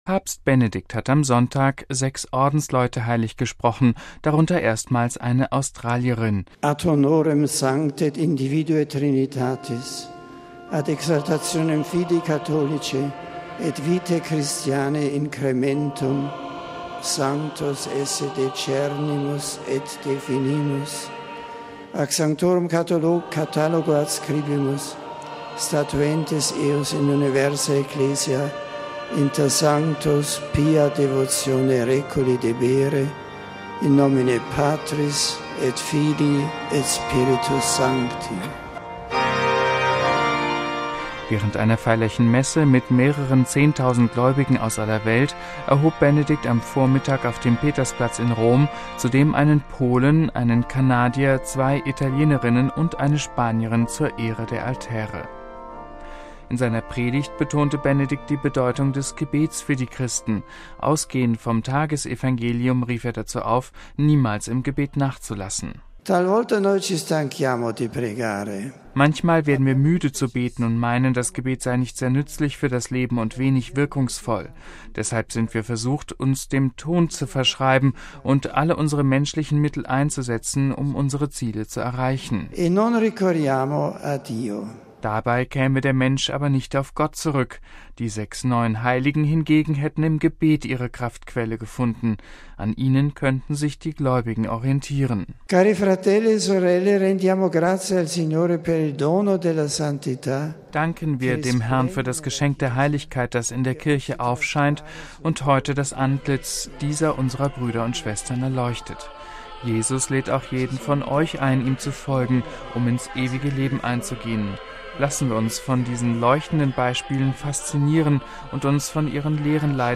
Während einer feierlichen Messe mit mehreren zehntausend Gläubigen aus aller Welt erhob Benedikt XVI. am Vormittag auf dem Petersplatz in Rom zudem einen Polen, einen Kanadier, zwei Italienerinnen und eine Spanierin zur Ehre der Altäre.